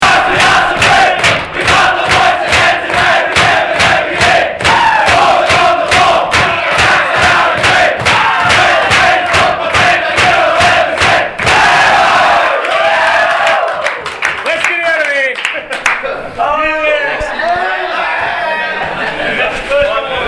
2011 Australian National Club U18 Championship, Newcastle KNIGHTS v Norths DEVILS
Kasey Badger and the Championship Final Ref's (Photo's : OurFootyMedia) Newcastle KNIGHTS - 2011 National U18 Club & SG Ball Cup Champions (Photo's : OurFootyMedia) KNIGHTS SING VICTORY SONG
ON FIELD |
newcastle_knights_sing_austClubChamps.mp3